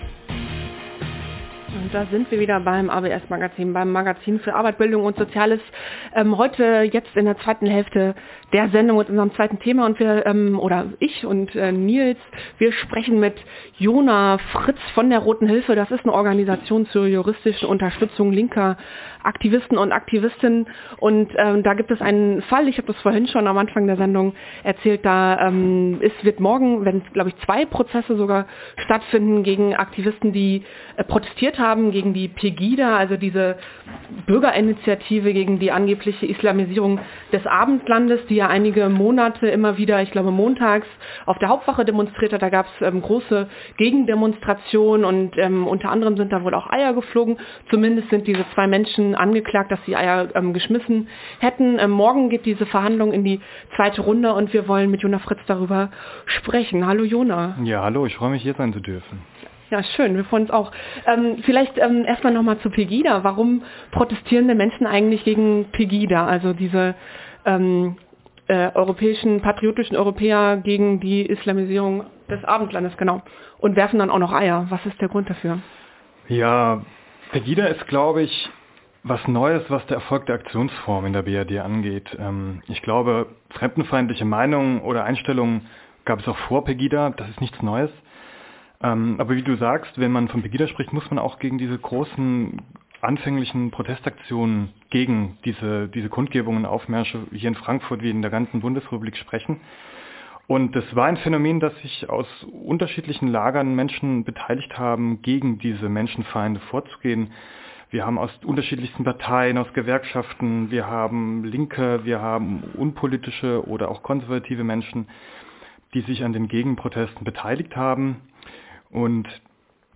Am 23. März 2016 war die Rote Hilfe zu Gast im Studio vom ABS-Magazin auf Radio X.